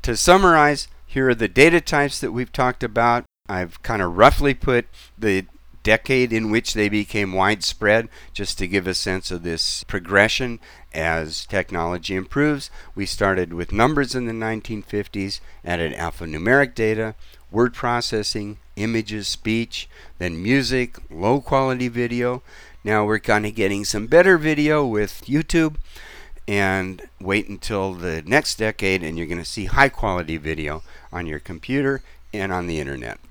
But, as you see, the volume was still quite uneven and there is clipping at several points, so I adjusted the levels using Levelator.